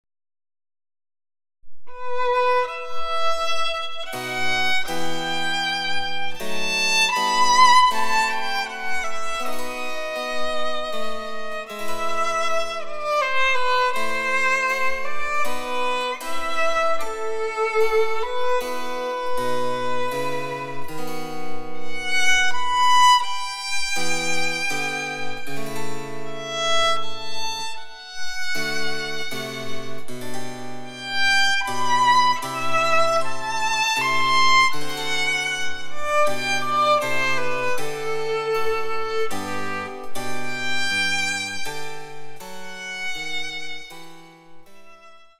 ４つの楽章から成り、緩・急・緩・急の構成です。
■ヴァイオリンによる演奏（ホ短調）
チェンバロ（電子楽器）